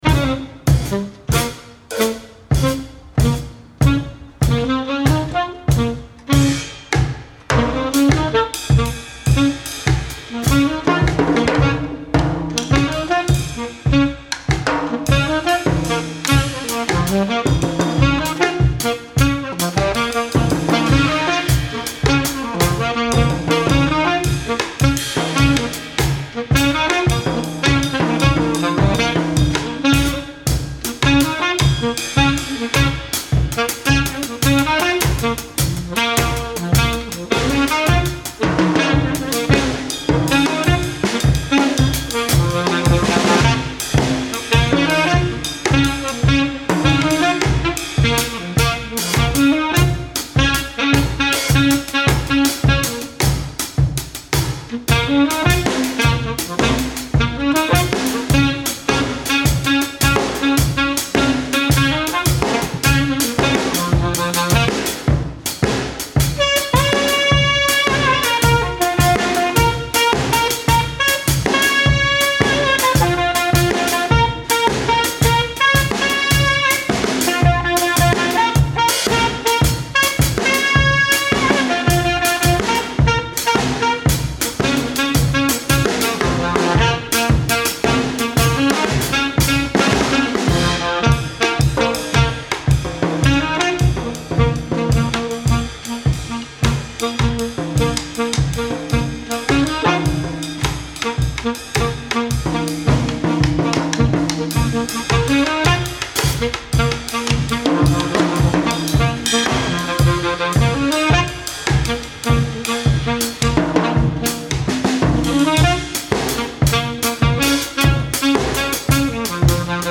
Recorded live at the 39th Street loft in Brooklyn.
alto saxophone, samplers, electronics
drums, alto saxophone
Stereo (722 / Pro Tools)